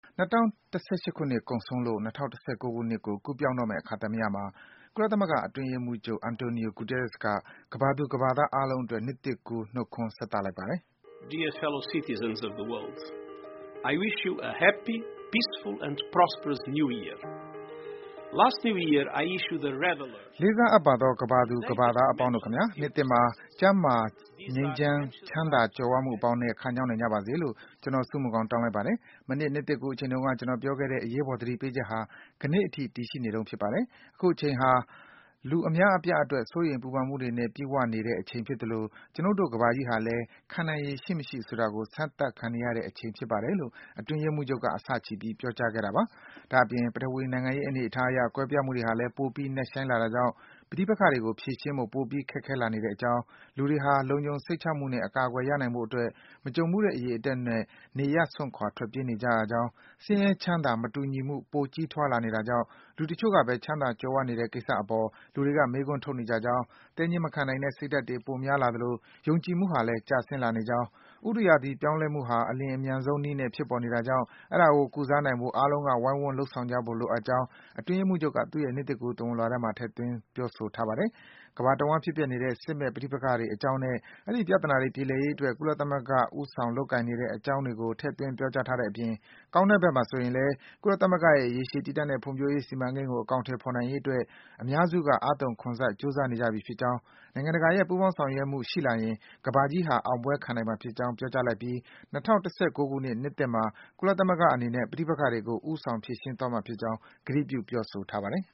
၂၀၁၈ ခုနှစ် ကုန်ဆုံးလို့ ၂၀၁၉ ခုနှစ်ကို ကူးပြောင်းတော့မယ့် အခါသမယမှာ ကုလသမဂ္ဂ အတွင်းရေးမှူးချုပ် Antonio Guterres က ကမ္ဘာသူ ကမ္ဘာသား အားလုံးအတွက် နှစ်သစ်ကူး နှုတ်ခွန်း ဆက်သလိုက်ပါတယ်။